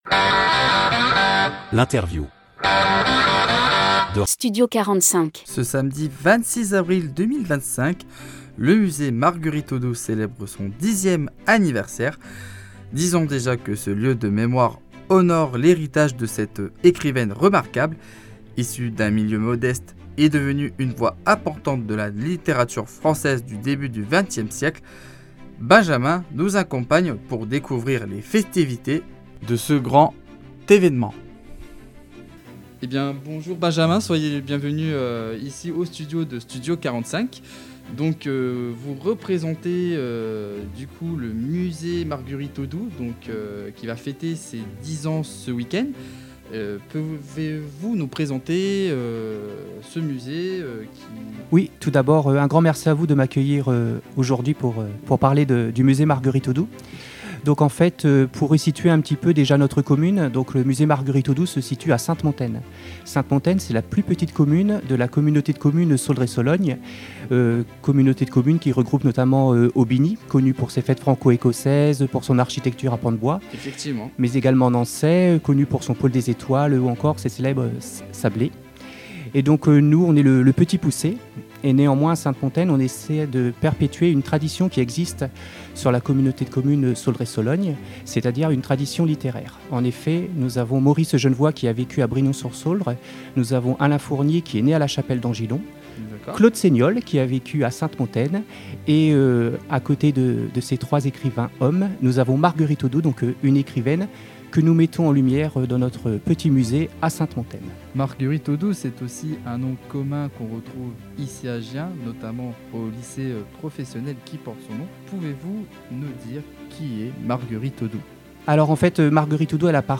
Interview Studio 45